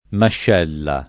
mascella [ mašš $ lla ] s. f.